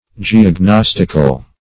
Search Result for " geognostical" : The Collaborative International Dictionary of English v.0.48: Geognostic \Ge`og*nos"tic\, Geognostical \Ge`og*nos"tic*al\, a. [Cf. F. g['e]ognostique.]